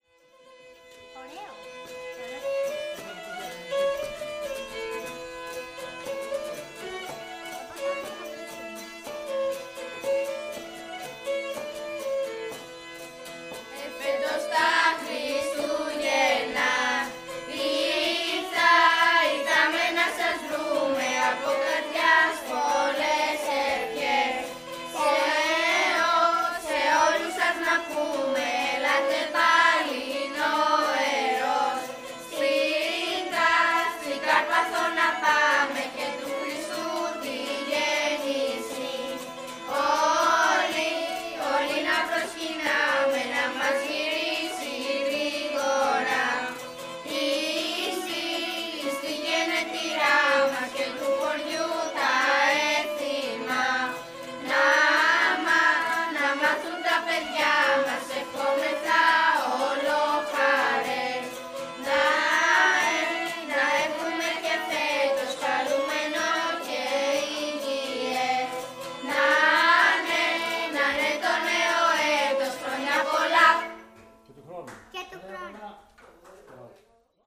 Περιγραφή: Παραδοσιακά Κάλαντα Καρπάθου, συνοδεία καρπαθιακών οργάνων
Λύρα
Λαούτο
παιδική και εφηβική χορωδία του Συλλόγου Απεριτών Αττικής.
Περιοχή: Στέγη του Συλλόγου, Μαρούσι, Αθήνα.
Συσκευές: ZOOM D6, Audio Technica AT-825 + ZOOM XY
Mixdown: Audacity to 2 channel stereo, pan hard LR